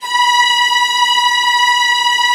VIOLINS C#-R.wav